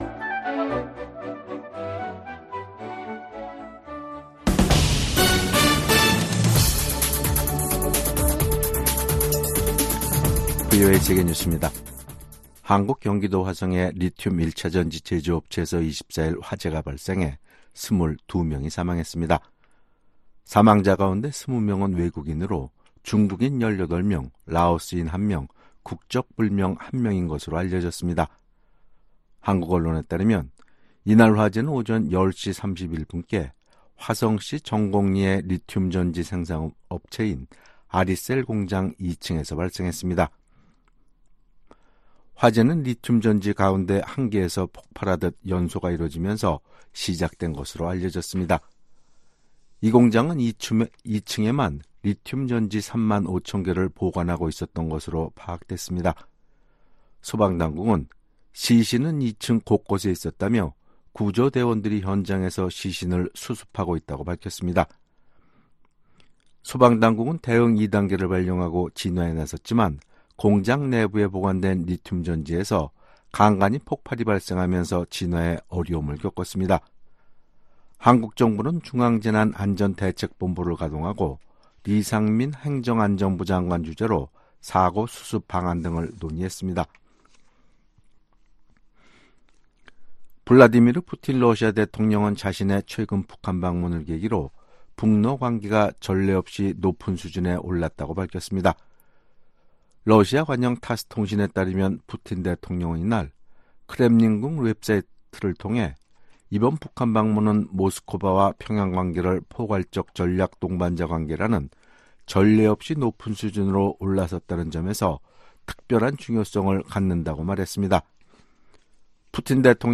VOA 한국어 간판 뉴스 프로그램 '뉴스 투데이', 2024년 6월 24일 3부 방송입니다. 북한 동창리 서해위성발사장에 대규모 굴착 작업 흔적이 들어났습니다.